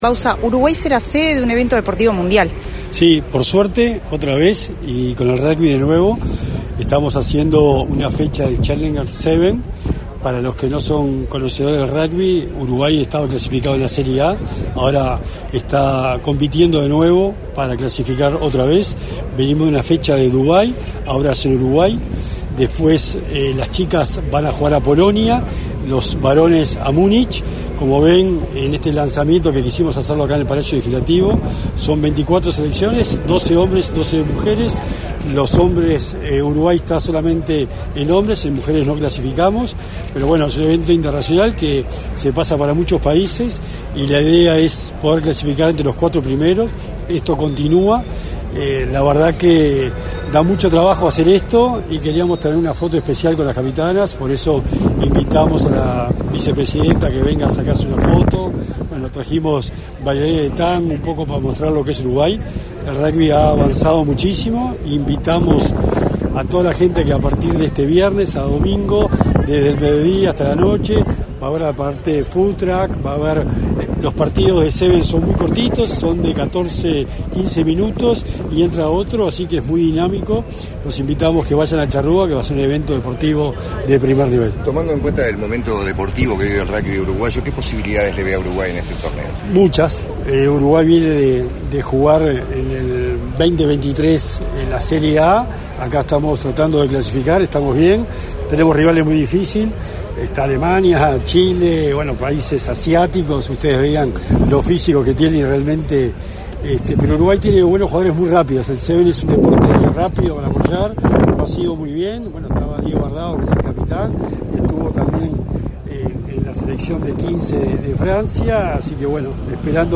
Declaraciones a la prensa del secretario nacional de Deporte, Sebastián Bauzá
Al finalizar el evento, el secretario nacional de Deporte, Sebastián Bauzá, realizó declaraciones a la prensa.